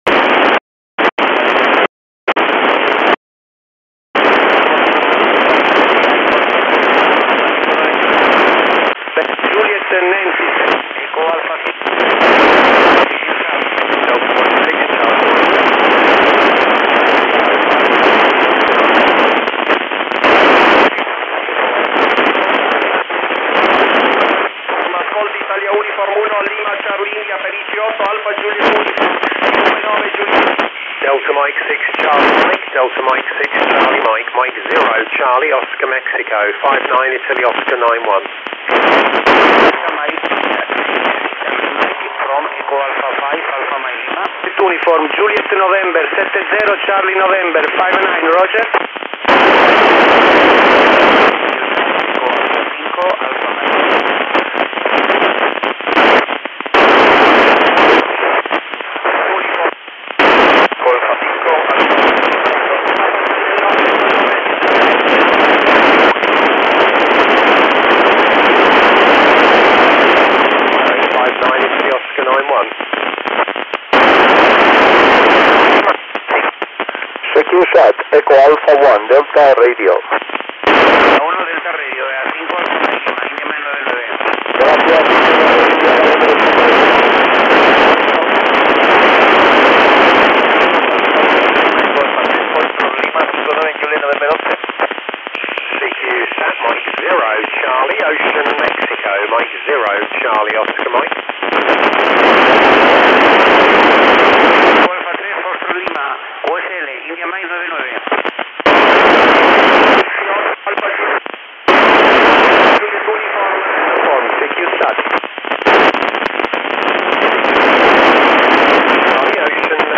Man hört einen Überflug des Repeaterbetriebes der ISS.